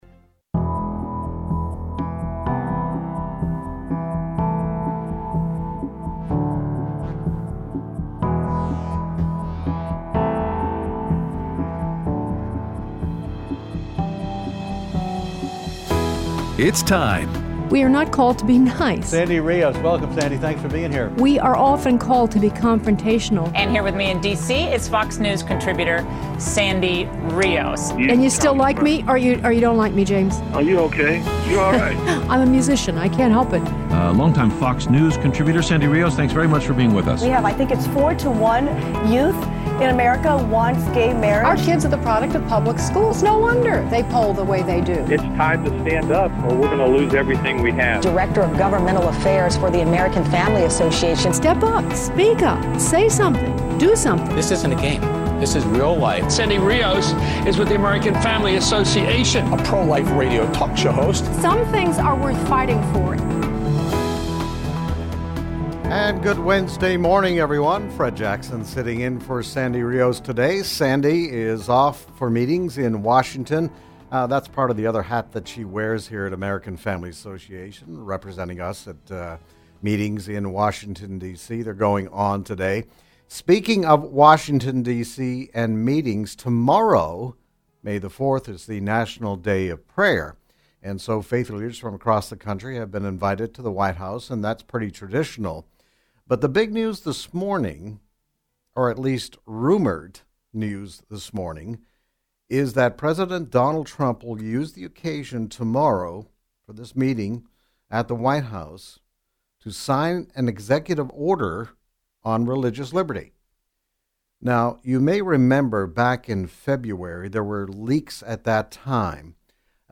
Guest Host
Took Phone Calls
Aired Wednesday 5/03/17 on AFR 7:05AM - 8:00AM CST